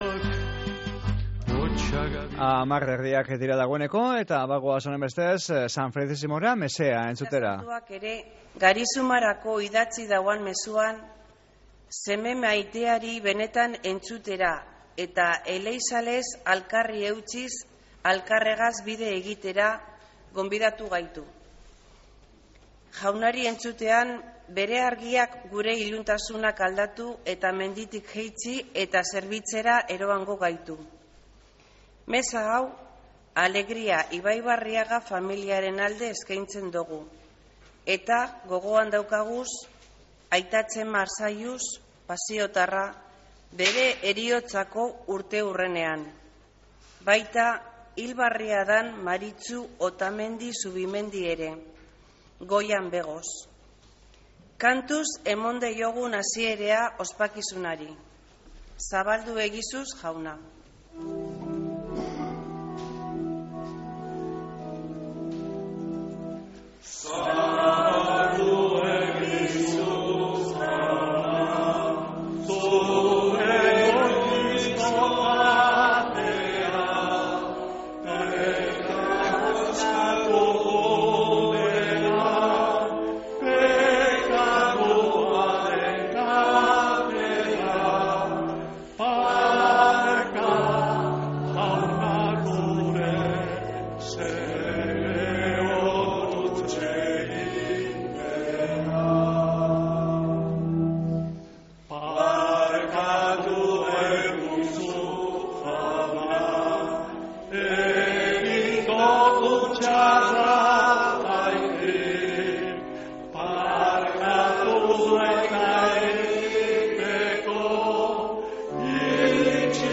Mezea San Felicisimiotik | Bizkaia Irratia